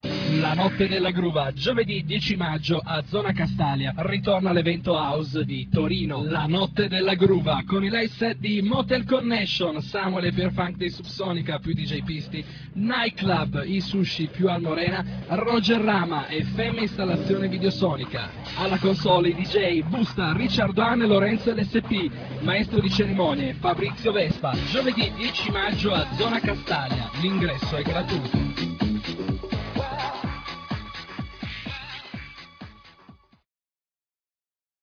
Si parla di House, regina incontrastata nelle dance-hall torinesi del terzo millennio battuta in quattro per l’occasione oltre che dal vinile, anche da bassi, batterie, sequencer ed intrecci vocali digitalizzati.
L’evento, che rappresenta quanto più di nuovo Torino abbia saputo sfornare nella attuale stagione ha i connotati di una festa house che non farà rimpiangere per intensità gli scenari del rock.